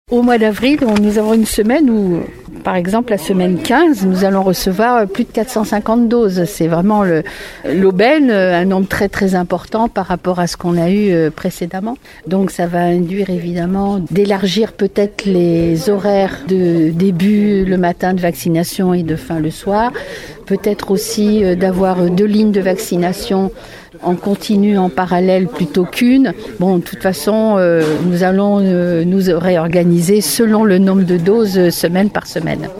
Ce que nous confirme Claude Balloteau, maire de Marennes-Hiers-Brouage :